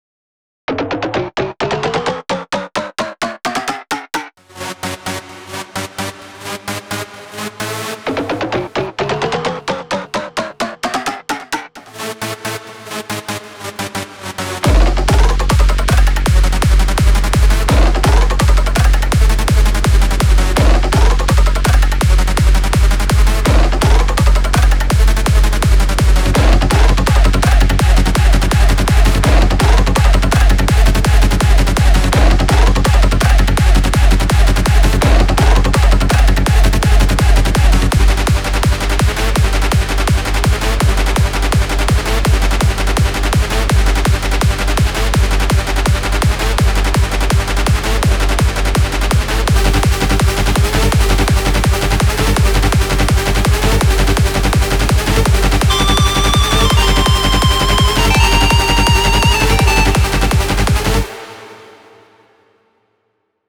אפשר אולי לגוון בסאונד בקטע השני, ואולי עדיף את זה במהירות יותר נמוכה, על כמה זה על 150?